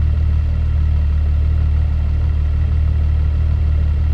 rr3-assets/files/.depot/audio/Vehicles/v12_07/v12_07_idle.wav
v12_07_idle.wav